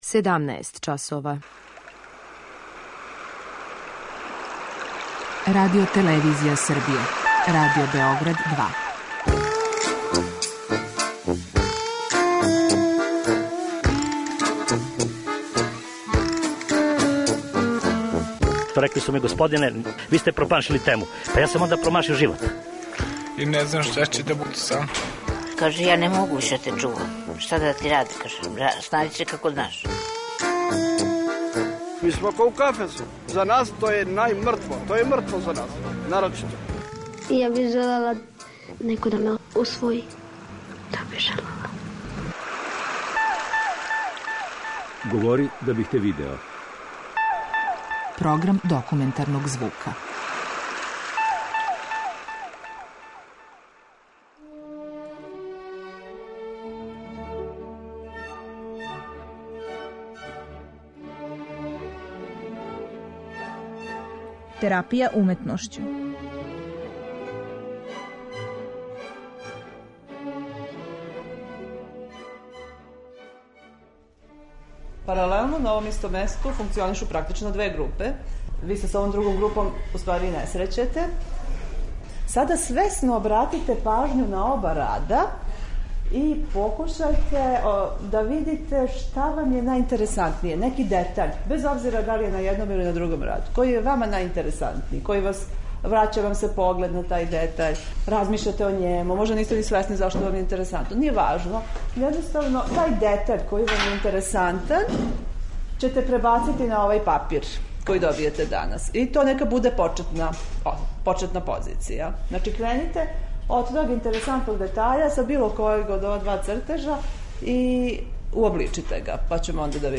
Документарни програм
Материјал је снимљен на изложби радова насталих у оквиру "Арт брут студија" ВМА у Културном центру "Град", као и на самим терапијским радионицама.